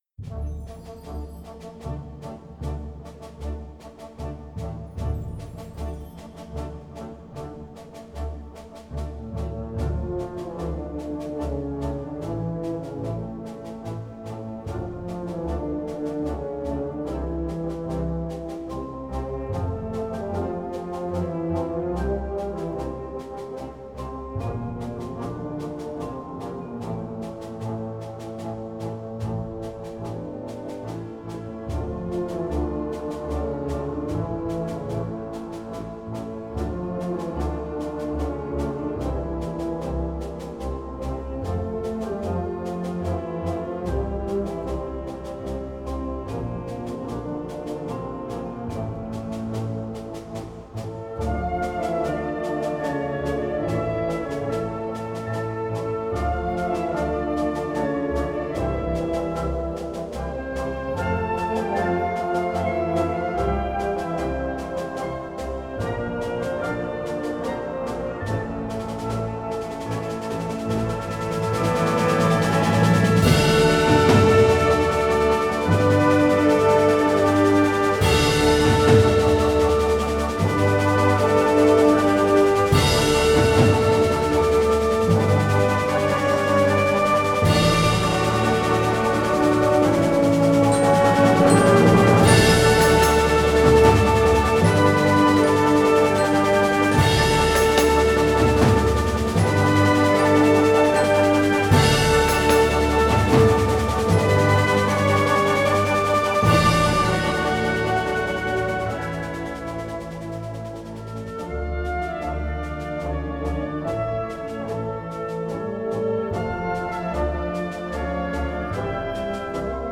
Partitions pour orchestre d'harmonie, ou - fanfare.
• Chœur ad lib.